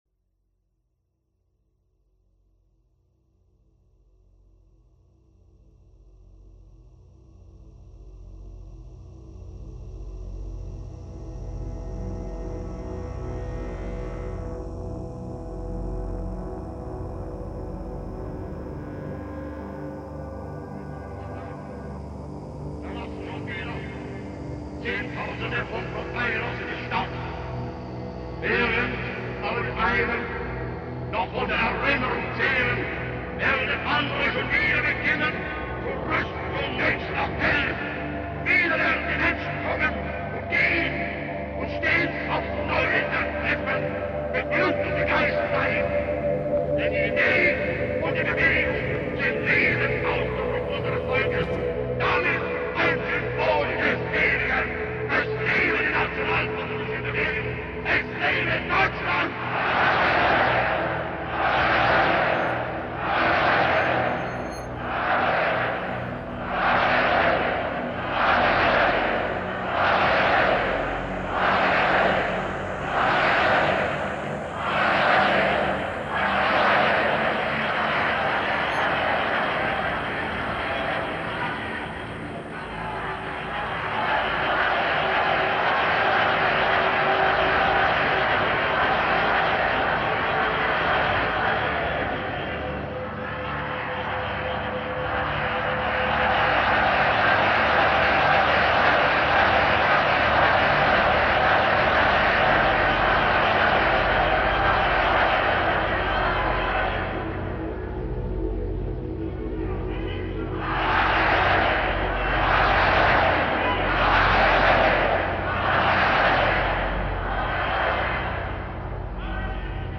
Każda audycja obfituje w masę niekonwencjonalnej muzyki, granej przez wyjątkowych, aczkolwiek bardzo często niszowych artystów.